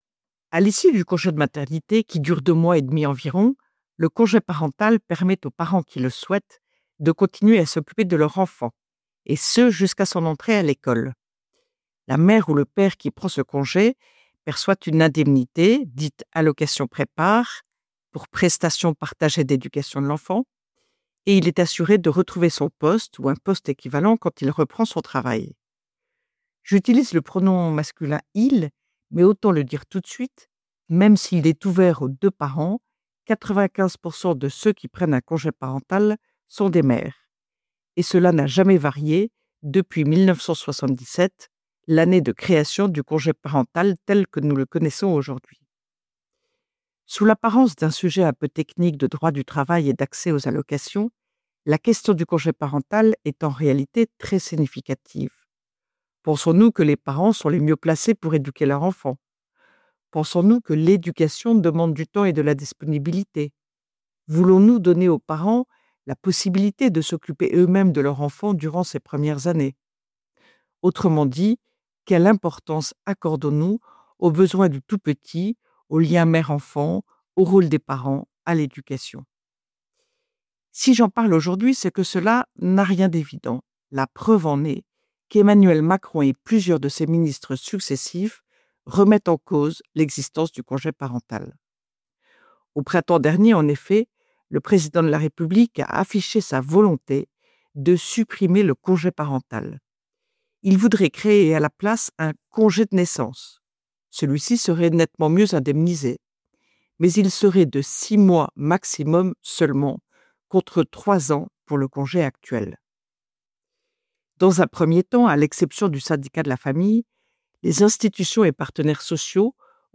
« Esprit de Famille » : Retrouvez chaque semaine la chronique de Ludovine de La Rochère, diffusée le samedi sur Radio Espérance, pour connaître et comprendre, en 3 minutes, l’essentiel de l’actualité qui concerne la famille.